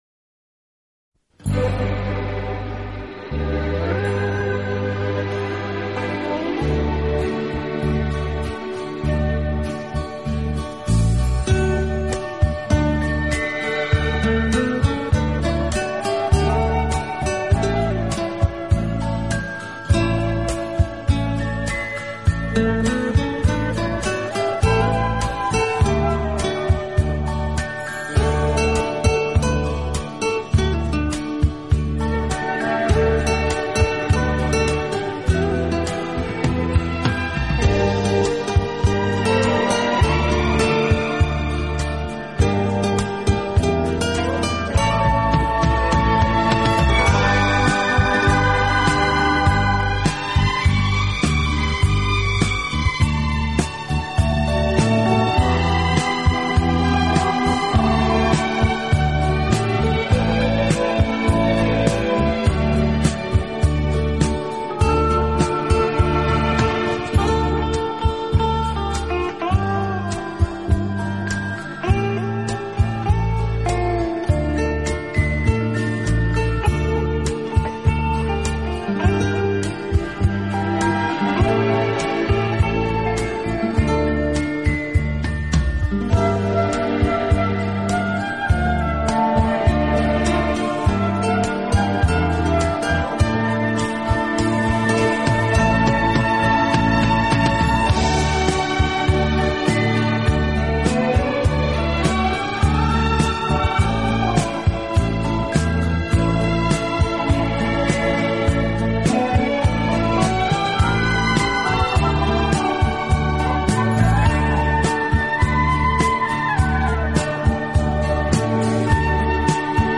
依然梦幻而美丽。